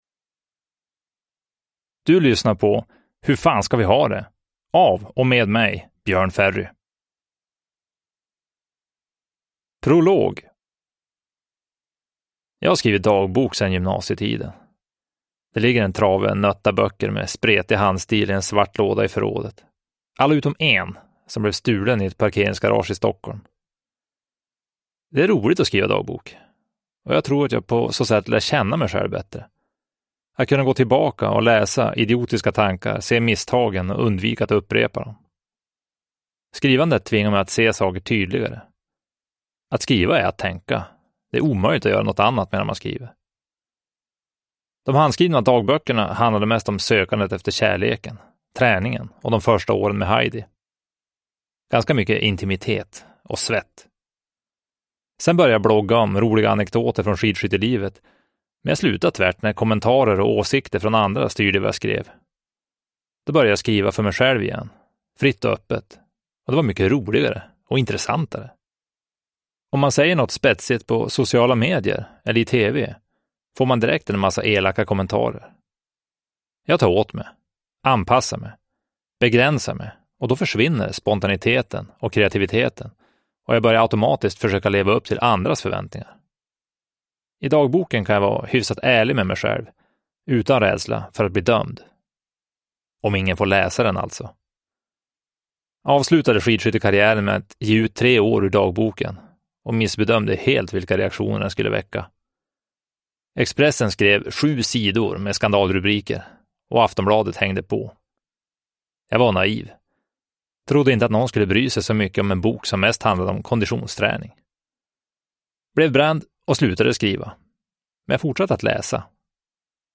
Hur fan ska vi ha det? : Dagbok 2022/2023 – Ljudbok – Laddas ner
Uppläsare: Björn Ferry